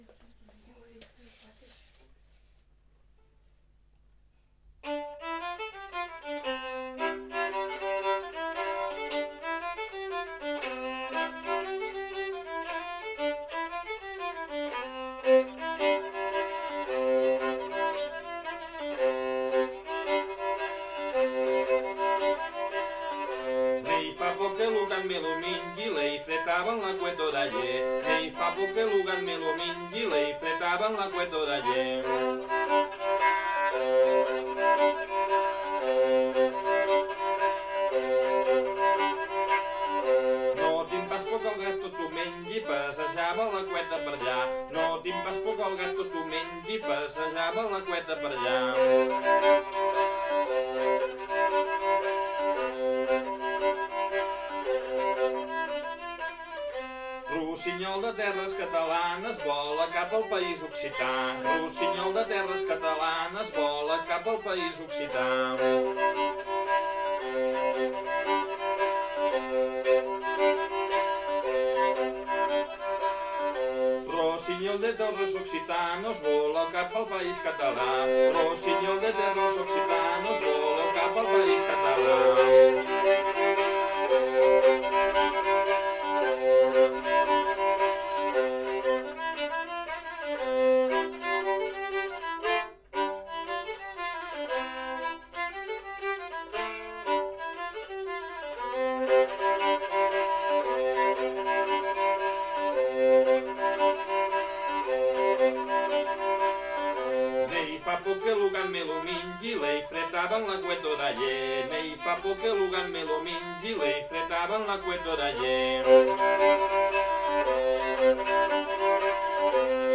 Genre : chanson-musique
Type de voix : voix d'homme
Instrument de musique : violon